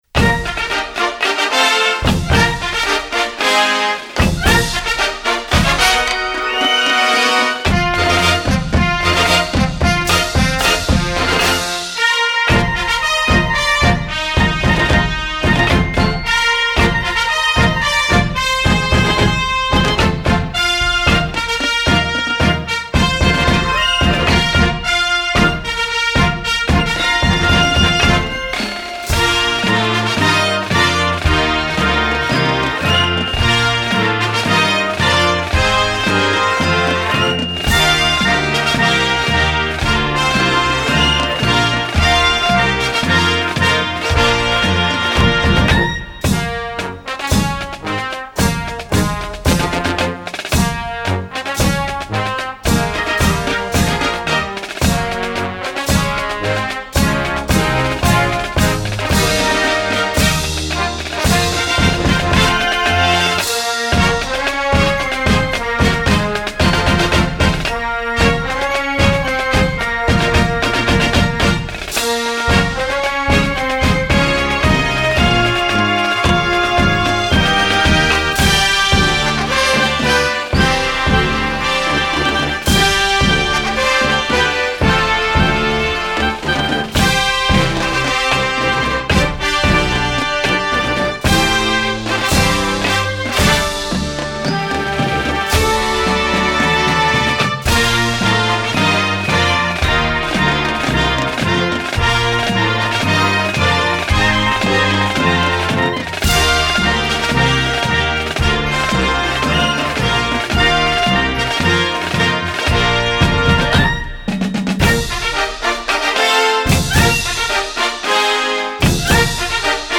Reihe/Serie: Marching-Band
Besetzung: Blasorchester